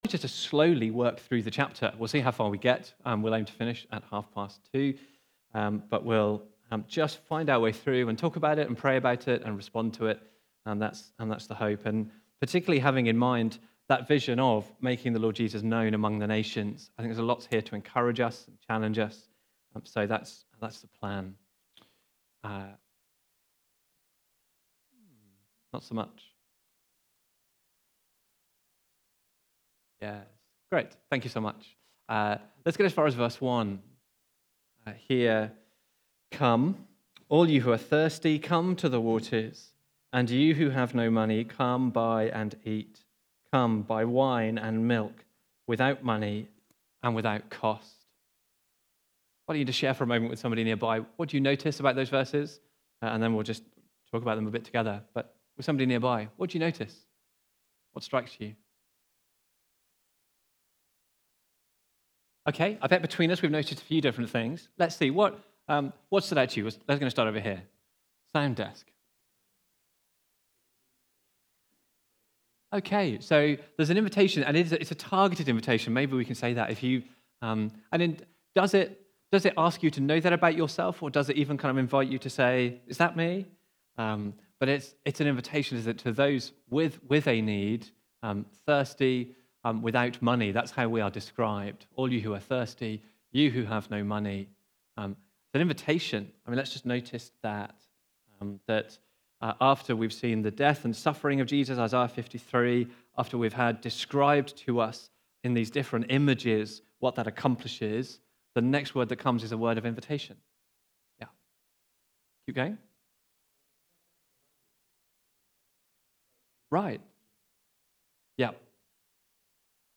Preaching
Discussion (Isaiah 55) from the series A Vision for 2024. Recorded at Woodstock Road Baptist Church on 01 September 2024.